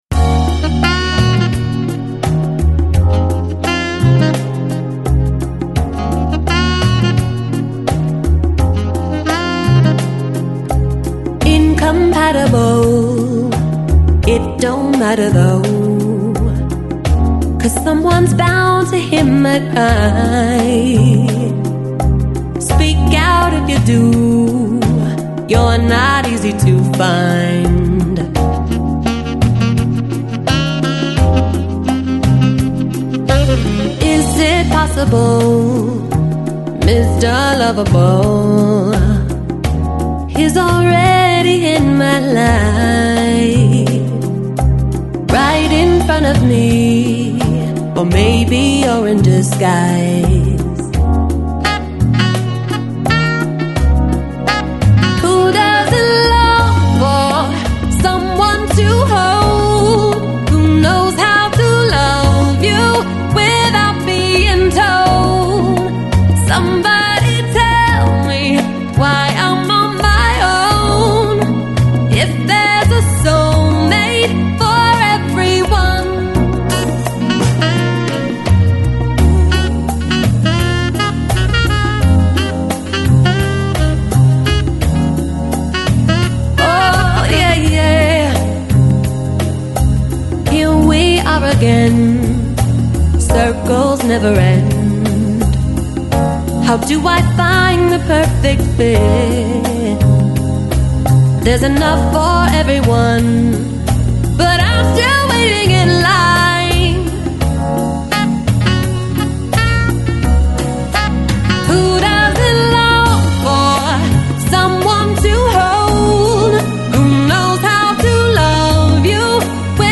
Downtempo, Lounge, Nu Jazz, Acid Jazz